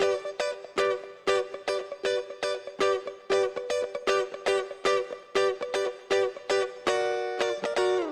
12 Guitar PT2.wav